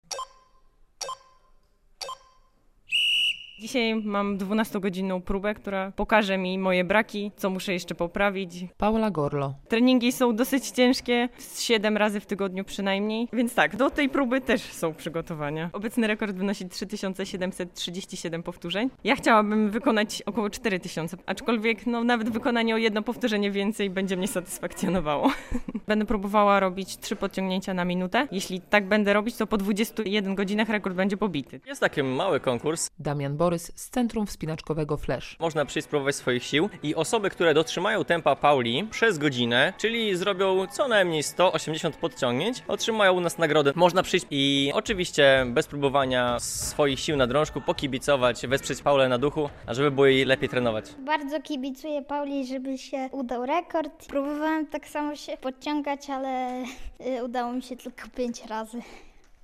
relacja
W sobotę (31.07) sportsmenka sprawdzała swoją formę na otwartym treningu w białostockim centrum wspinaczkowym Flash.